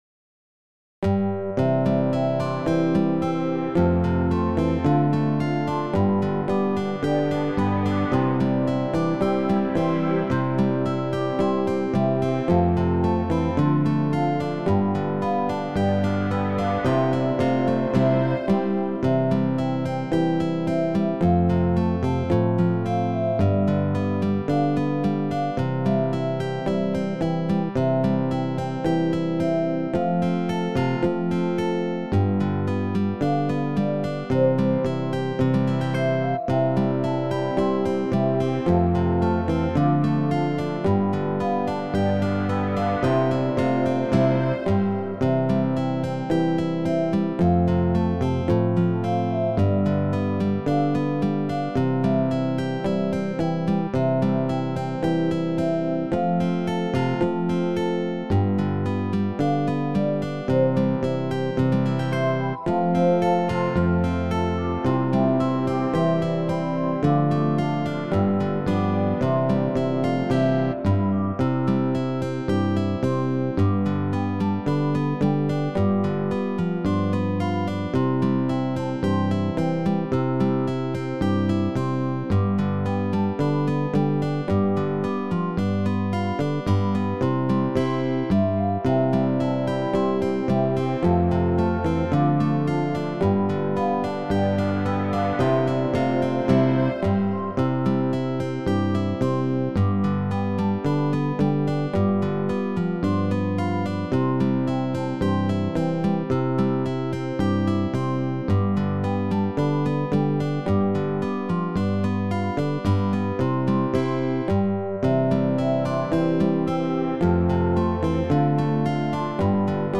MP3 accompaniment (C)